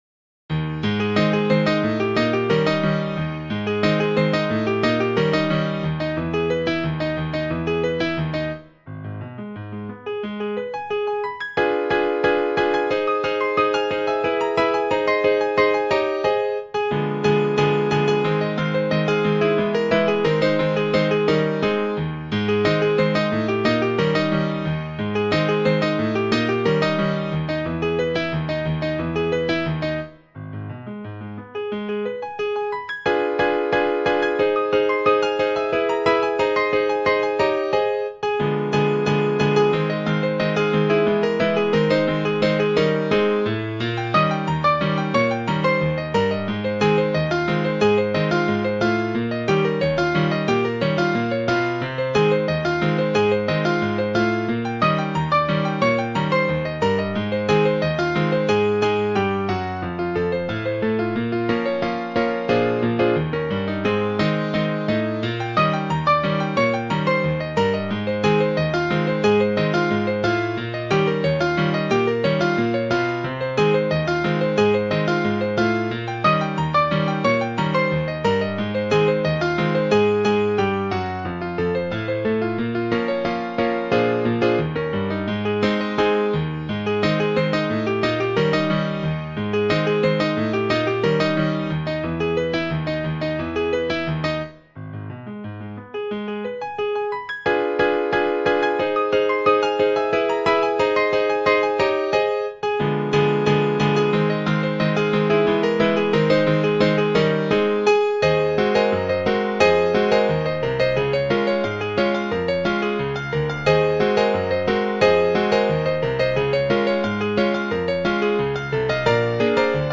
Ragtime Sheet Music
piano sheet music and audio